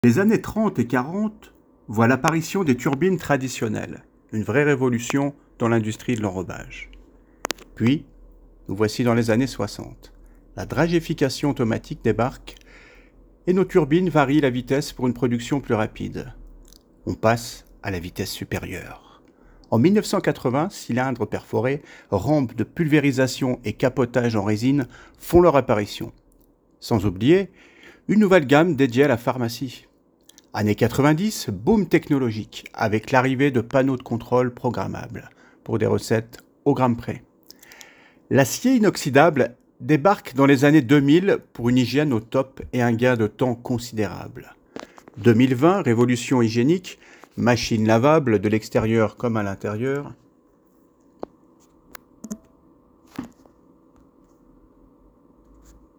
Extrait voix off